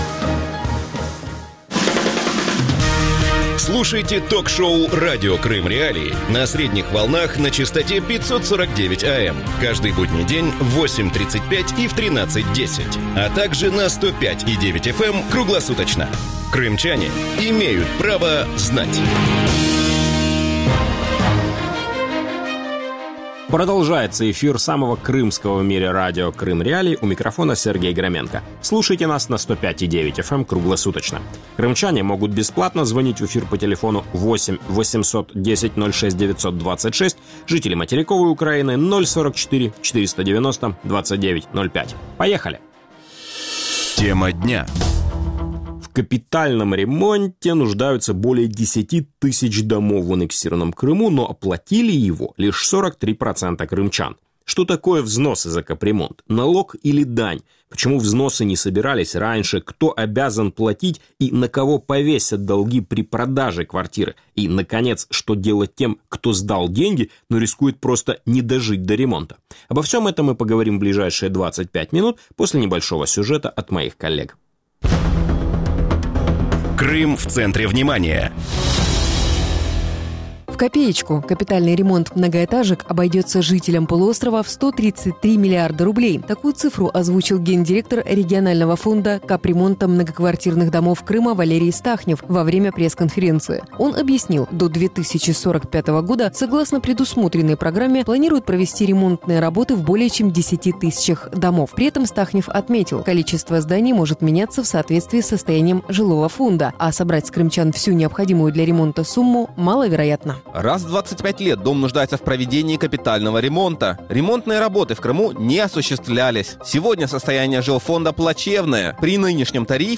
Кто обязан платить за капитальный ремонт домов, и на кого «повесят» долги при продаже квартиры? Гости эфира